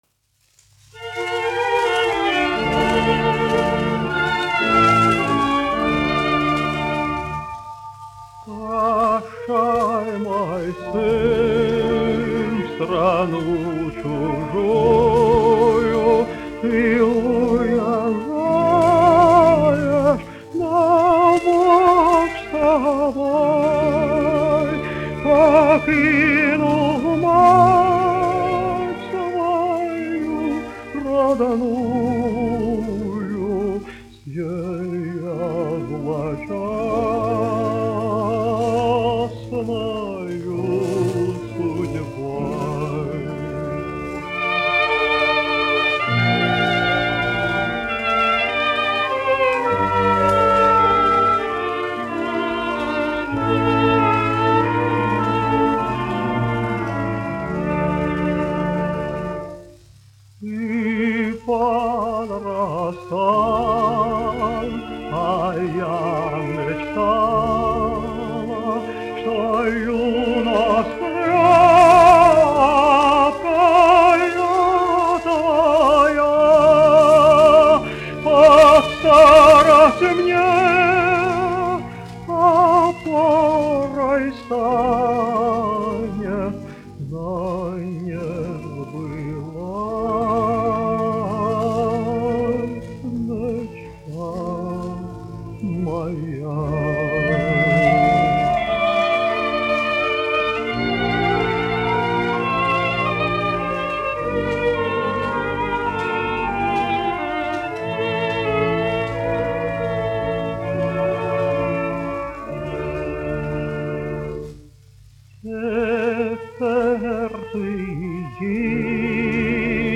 Прощай мой сын : народная песня
dziedātājs
1 skpl. : analogs, 78 apgr/min, mono ; 25 cm
Krievu tautasdziesmas